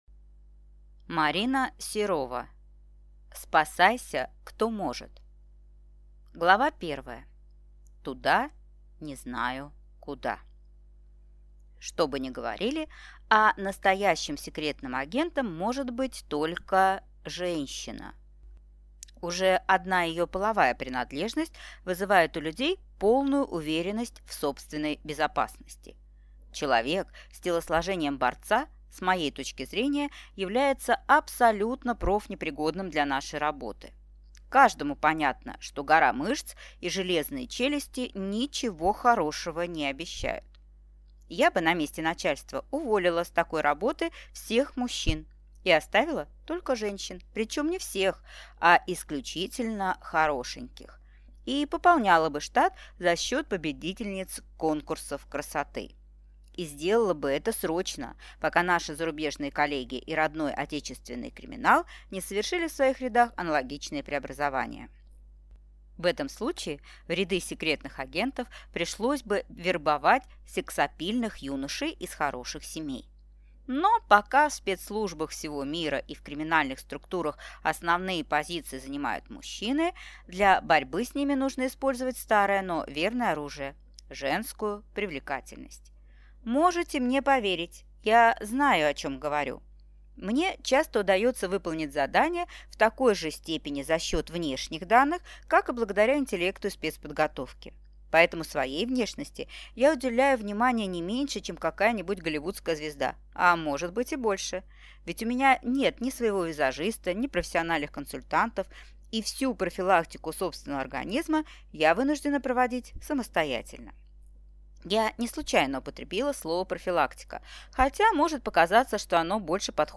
Аудиокнига Спасайся кто может | Библиотека аудиокниг